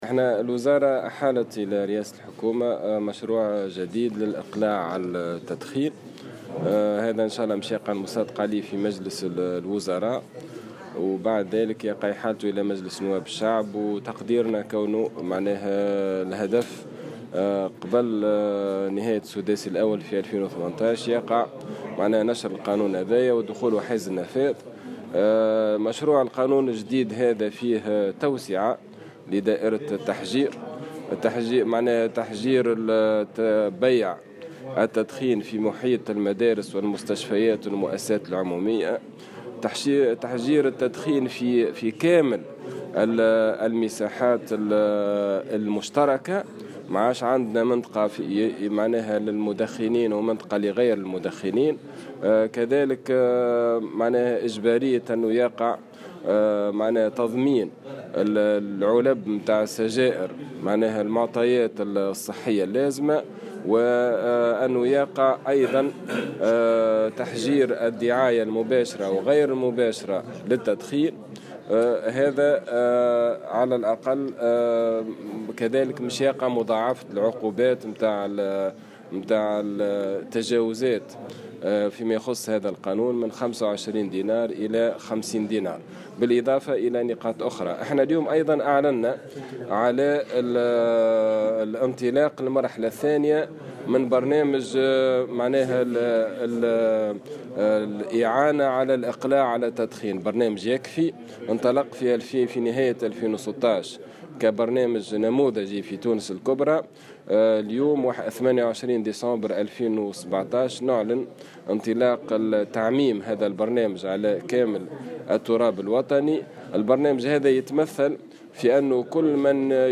وأوضح وزير الصحة عماد الحمامي اليوم الخميس في تصريح لمراسل الجوهرة اف ام، أنه سيتم بمقتضى هذا المشروع تحجير بيع التبغ في محيط المدارس والمستشفيات والمؤسسات العمومية، وكذلك المساحات العامة.